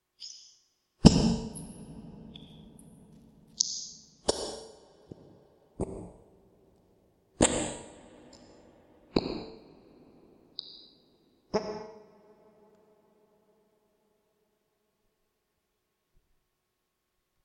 Dungeon Fart
描述：Someone farting in a dungeon, or possibly going to the bathroom in a very echoy restroom.Made using the magical power of wet handfarts.
标签： echo parp flatulation fart flatulate flatulence bathroom farts poot farting poop dungeon
声道立体声